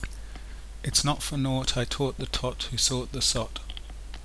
3. No, neutral form of the Queen's English I think.
what I sound like reading it.